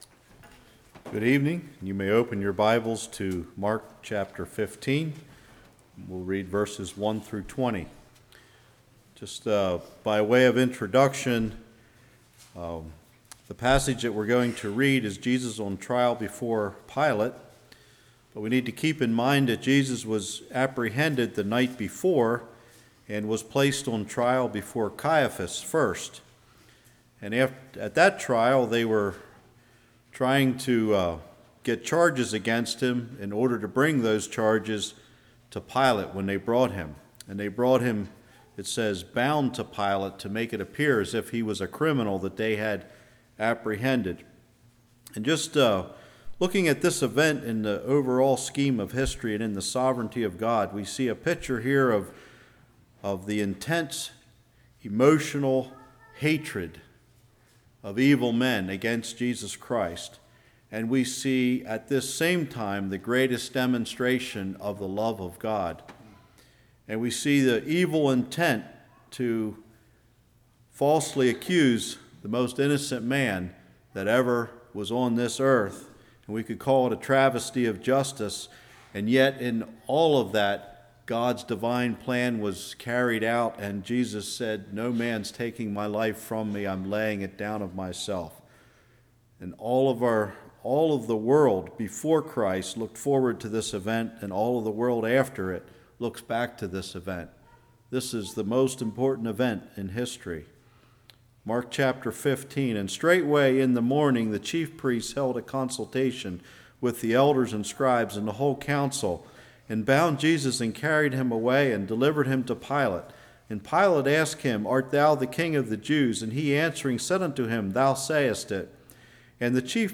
Series: Spring Lovefeast 2016